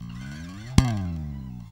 Index of /90_sSampleCDs/Roland L-CD701/BS _Jazz Bass/BS _E.Bass FX